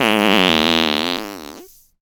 fart_squirt_12.WAV